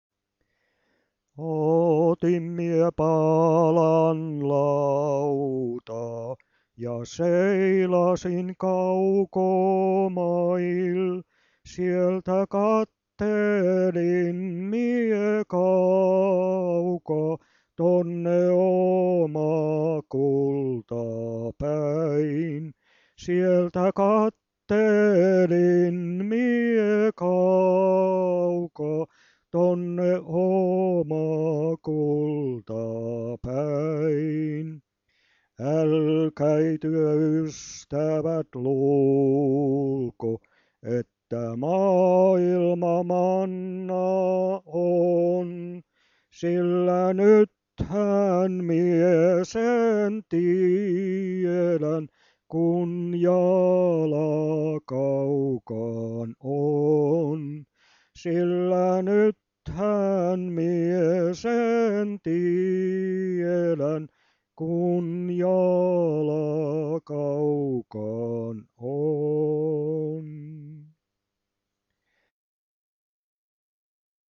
VANHOJA PIIRILEIKKILAULUJA